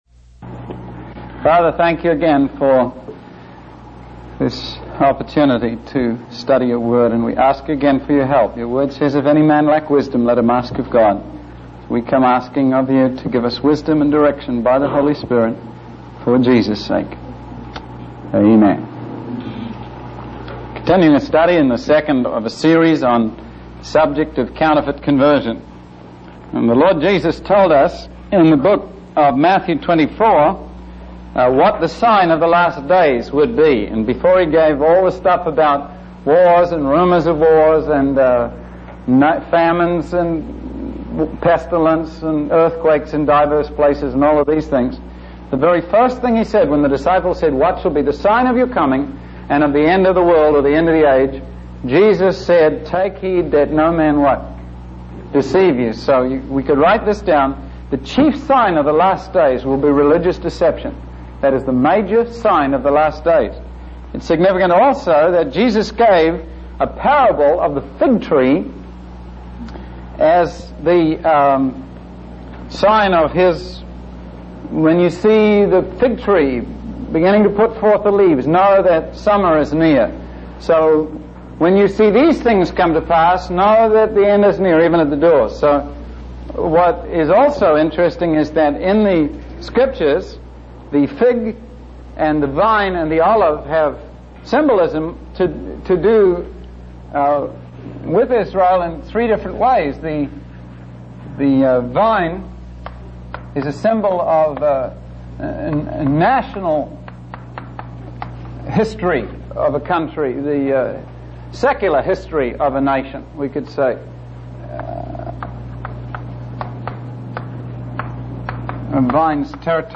In this sermon, the preacher emphasizes the simplicity of the gospel message.